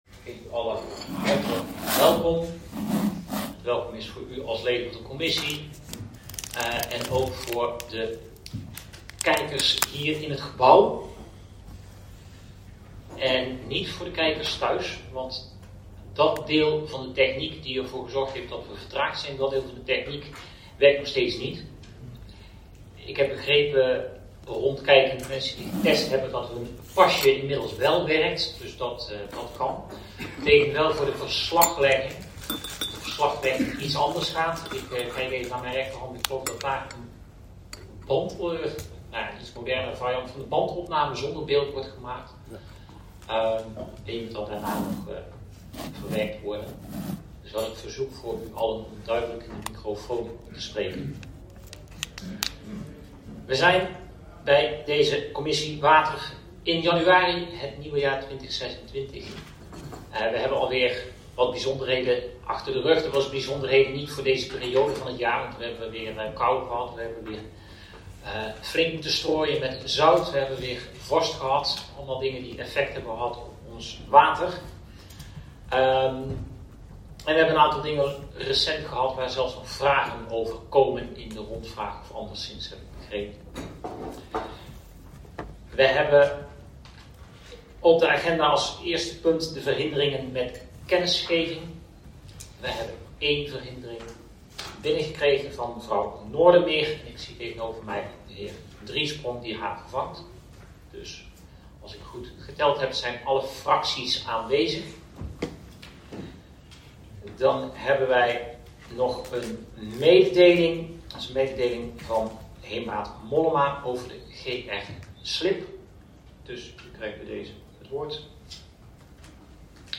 Waterschapshuis, IJsselmonde-zaal
Er is wel een geluidsopname van de commissie die als bijlage is toegevoegd.